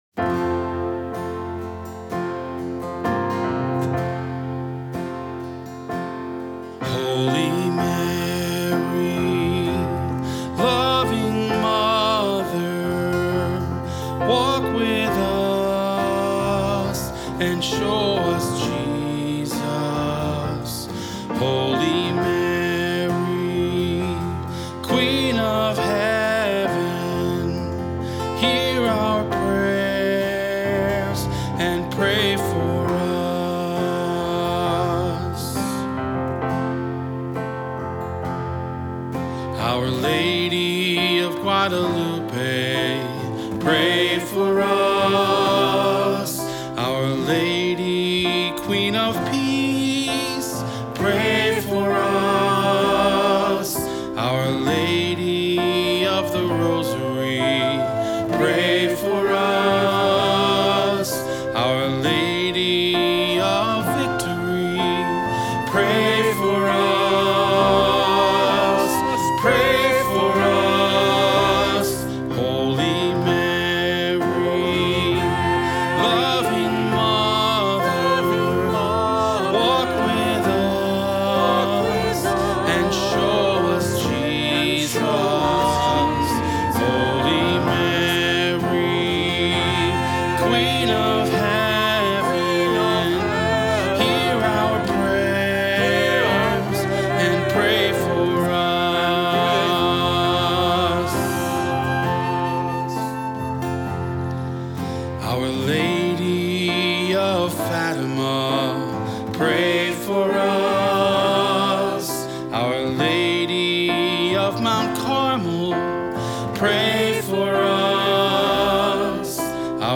Voicing: "Two-Part","Cantor","Assembly"